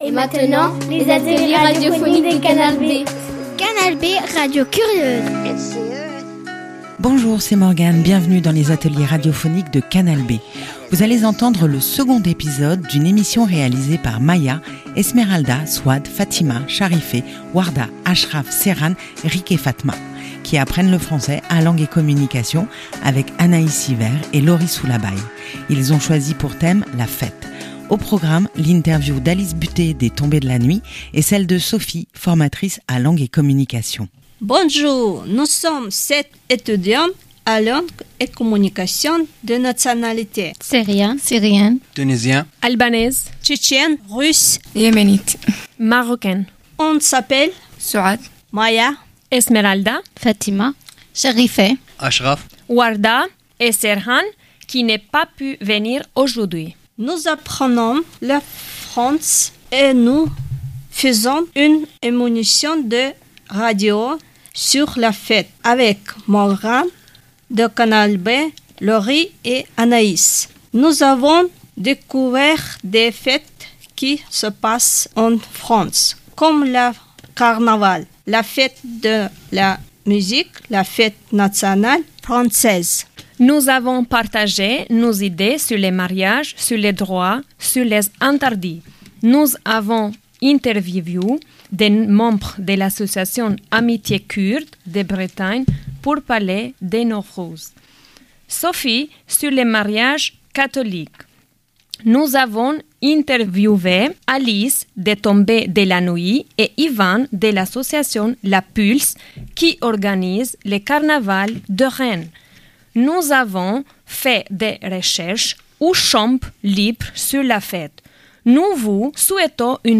Ils ont rencontré et interviewé, pour ce 2e épisode :